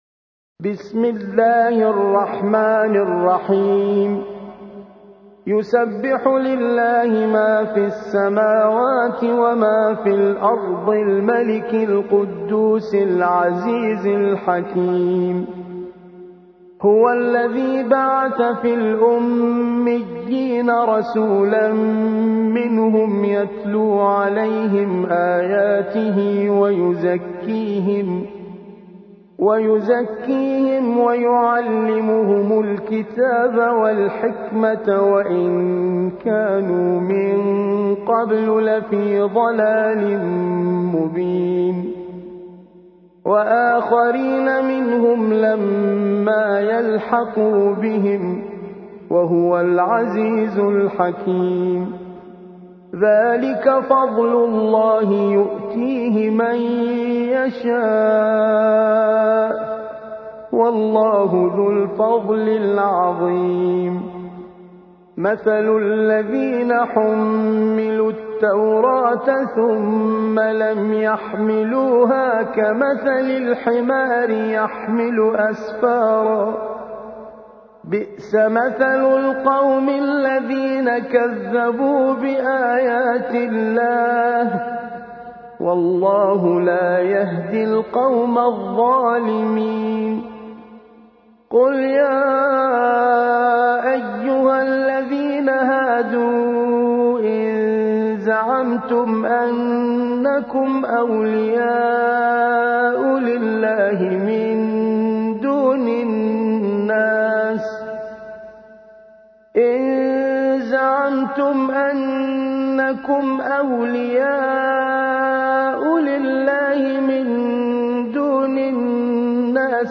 62. سورة الجمعة / القارئ